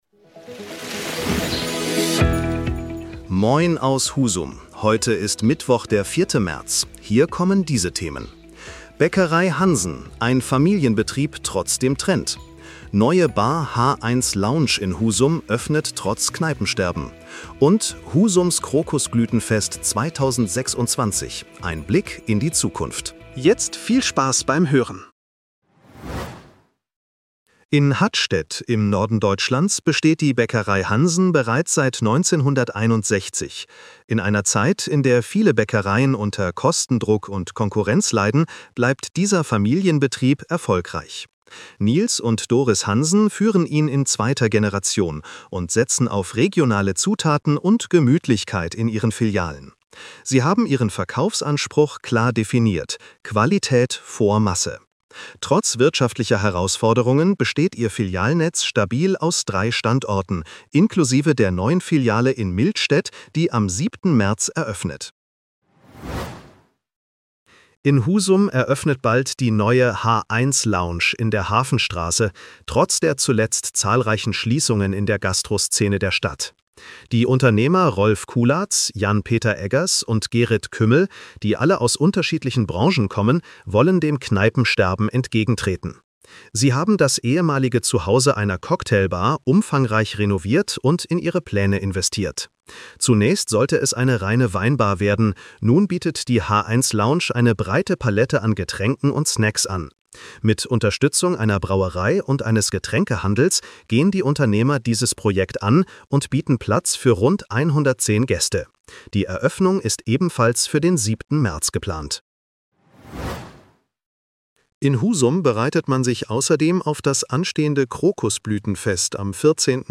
Was bewegt Husum heute? In unserem regionalen Nachrichten-Podcast